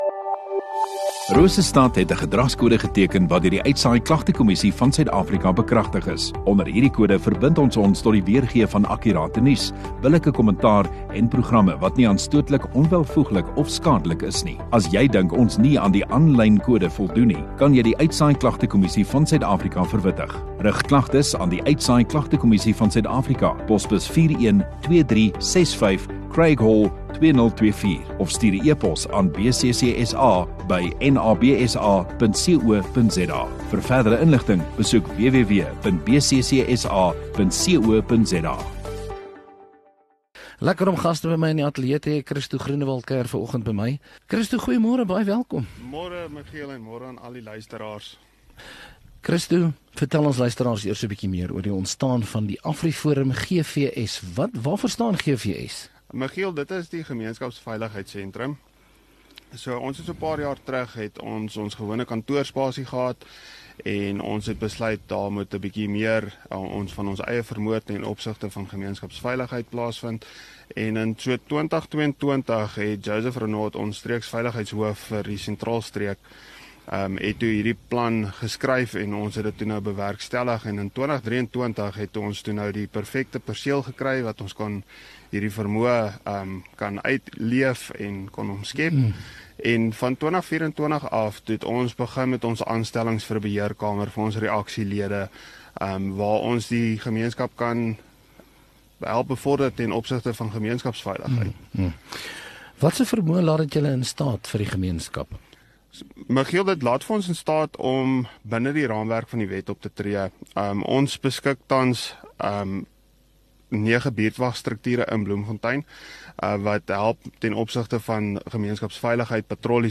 Radio Rosestad View Promo Continue Radio Rosestad Install Gemeenskap Onderhoude 26 Feb AfriForum